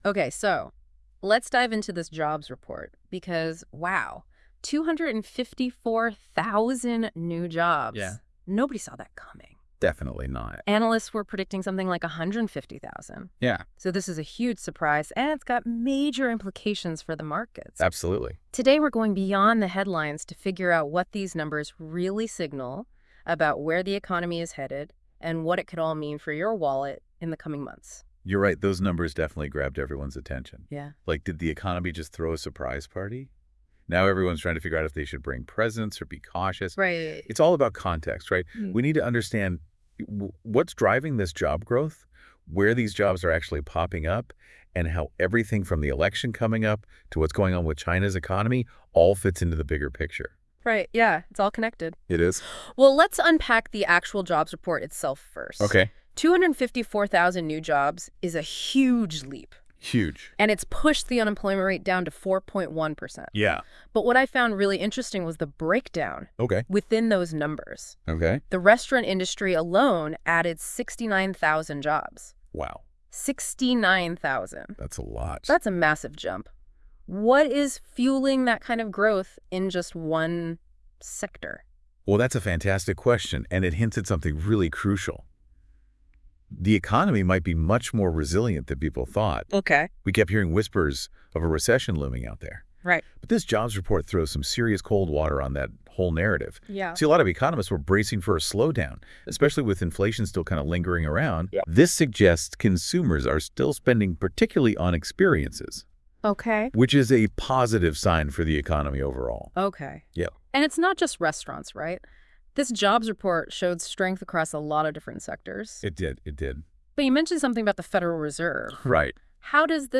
Also, listen to our new podcast developed by NotebookLM by Google AI.